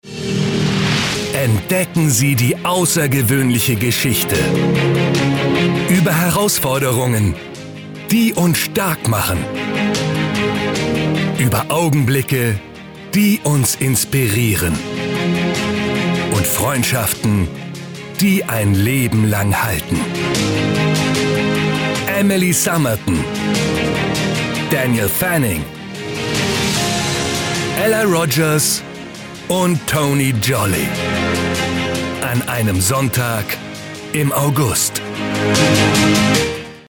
Movie Trailer